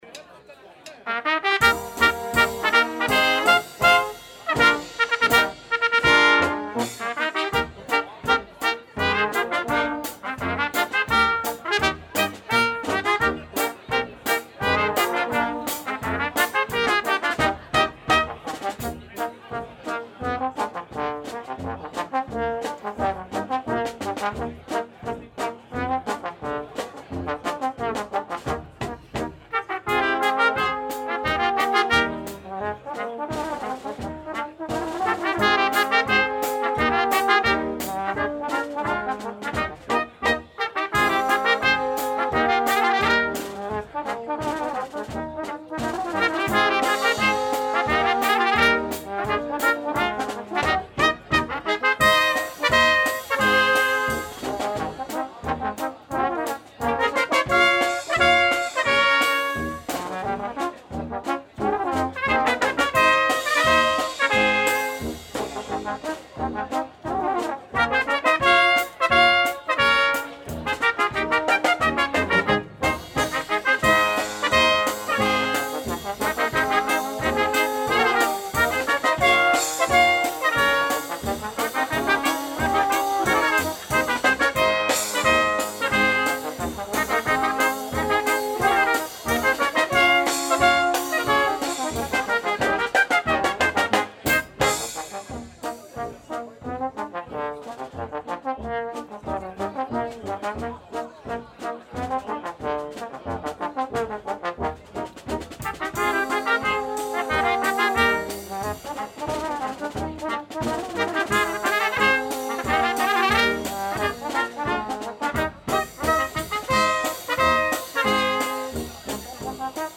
• Balkan/Ompa-ompa
• Tyroler
INSTR